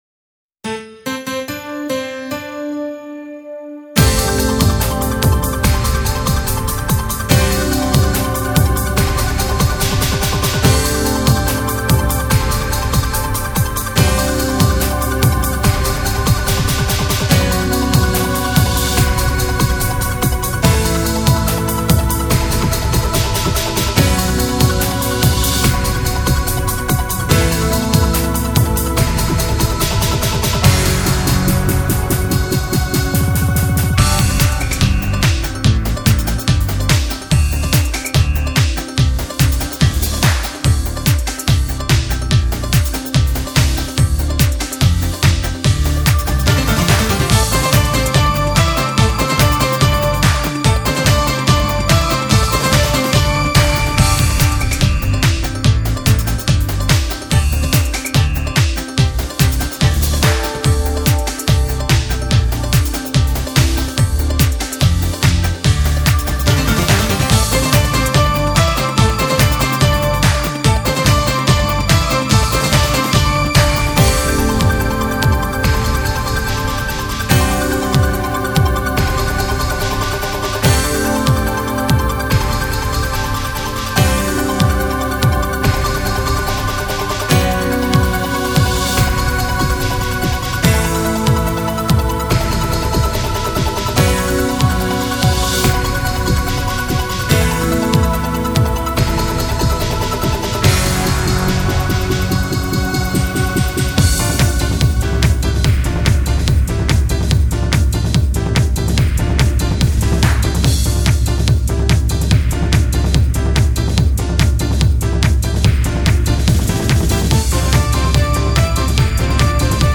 ダンスバージョン（踊り方は自由！）
kattakata_danceversion.mp3